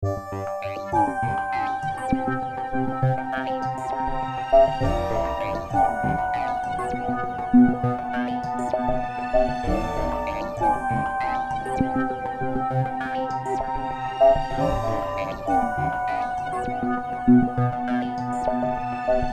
描述：臀部管弦乐
Tag: 100 bpm Hip Hop Loops Synth Loops 3.25 MB wav Key : Unknown